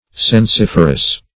sensiferous - definition of sensiferous - synonyms, pronunciation, spelling from Free Dictionary
Search Result for " sensiferous" : The Collaborative International Dictionary of English v.0.48: Sensiferous \Sen*sif"er*ous\, a. [L. sensifer; sensus sense + ferre to bear.] Exciting sensation; conveying sensation.